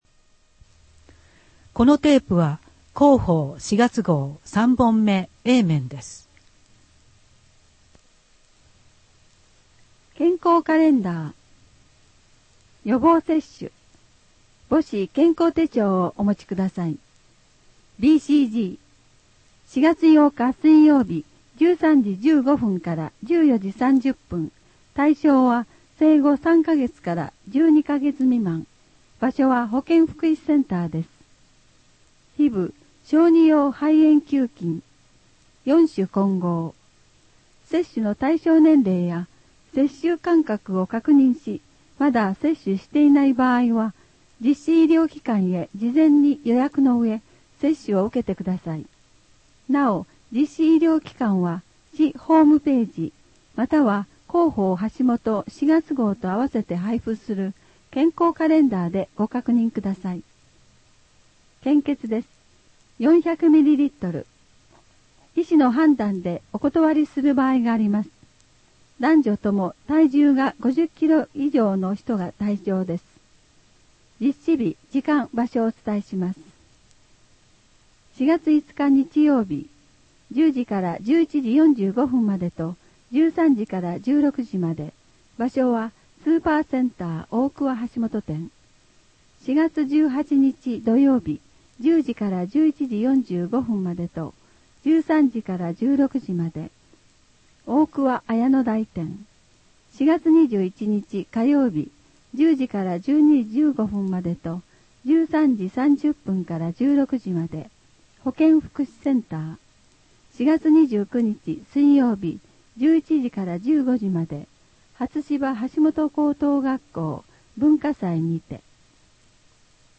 WEB版　声の広報 2015年4月号